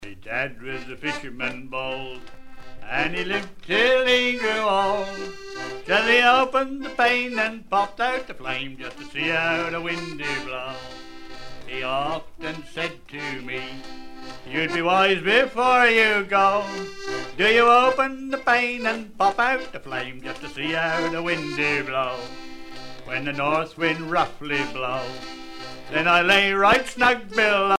circonstance : maritimes
Pièce musicale éditée